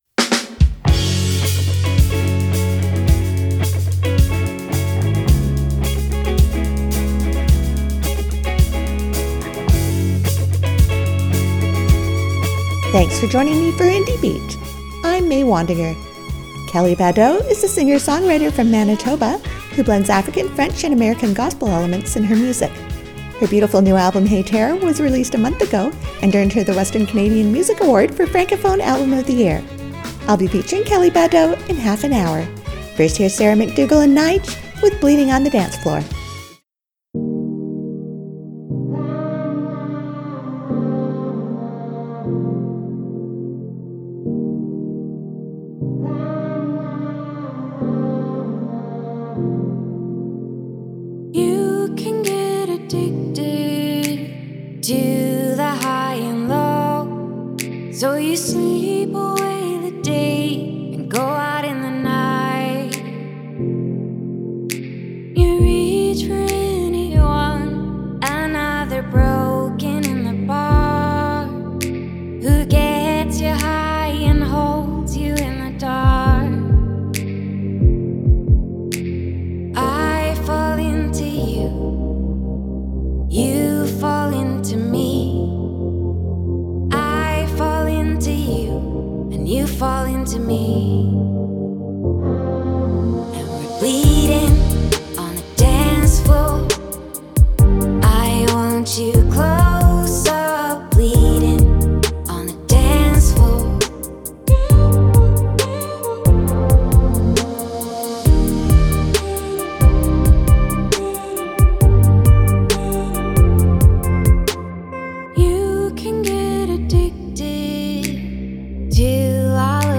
27 min of Western Canadian indie music mix